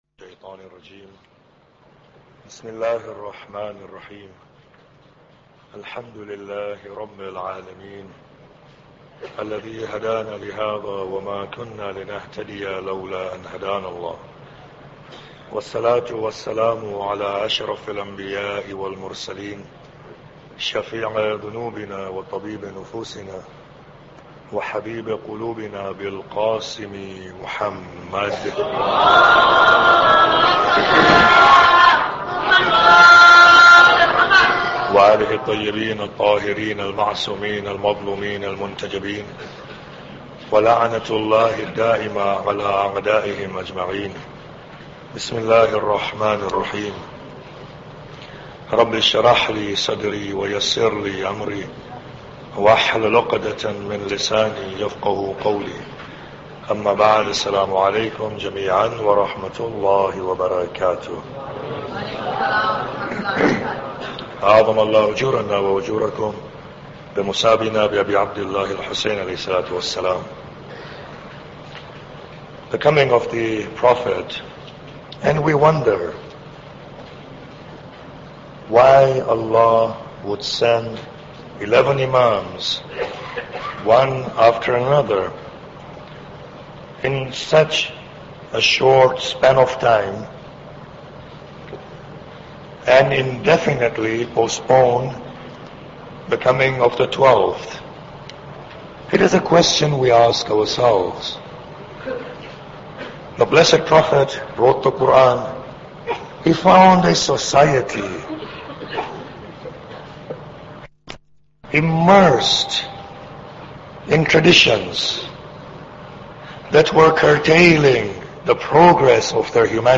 Muharram Lecture 4